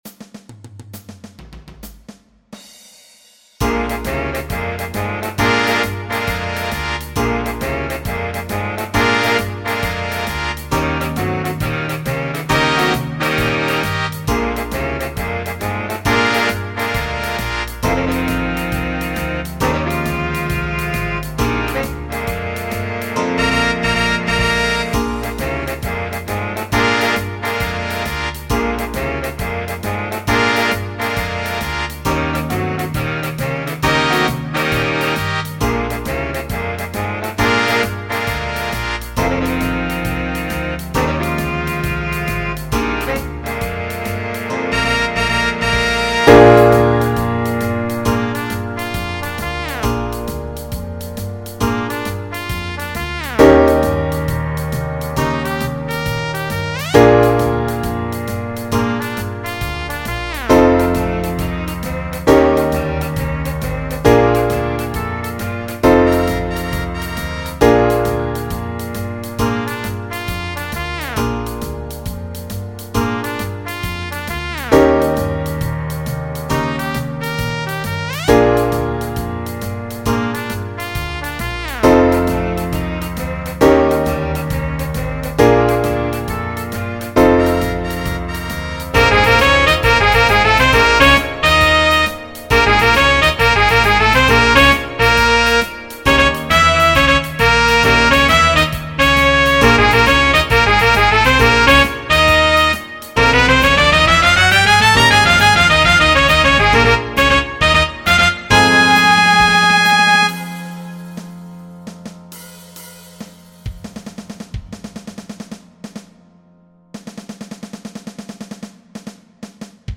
Smack That Thang-A 12 Bar Blues
I was inspired by jazz big bands, and especially the tune "Night Train" composed by Jimmy Forest.